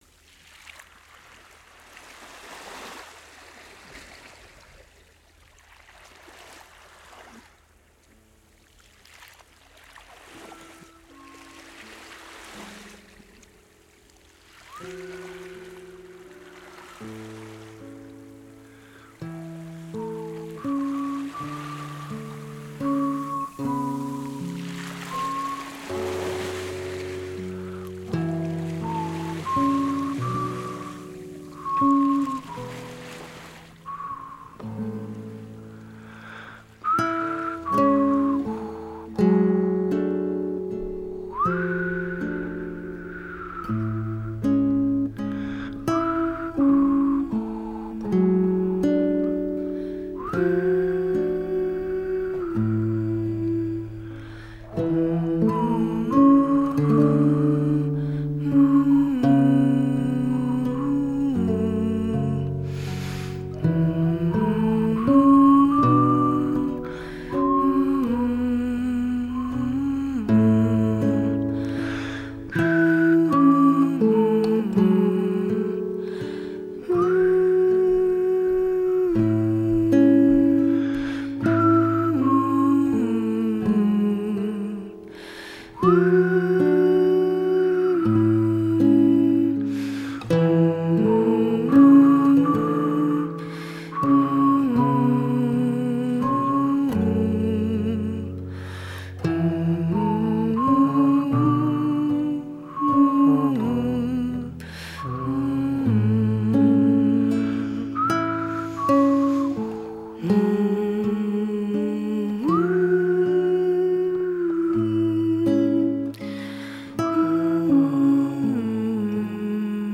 Els nens/es estan una estona escoltant una música de relaxació i a continuació duen a terme uns estiraments i controlen la respiració mentre entren a la calma.